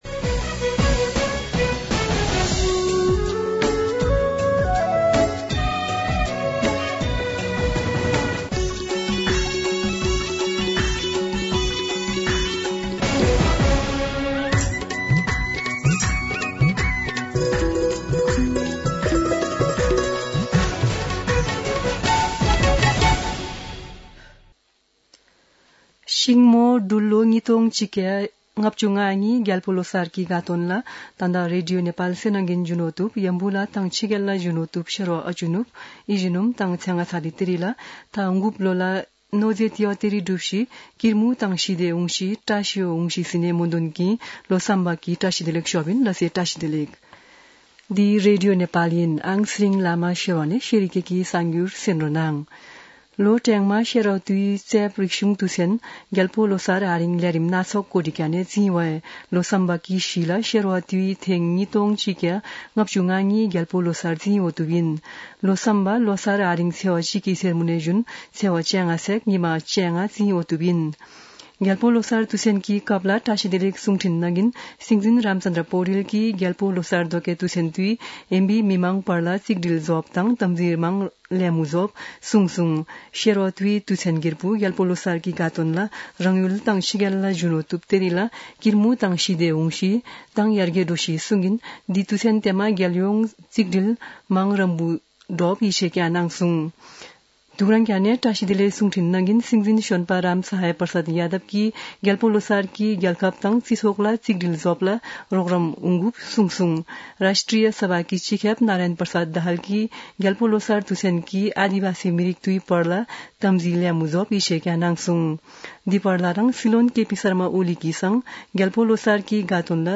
शेर्पा भाषाको समाचार : १७ फागुन , २०८१
Sherpa-News-4.mp3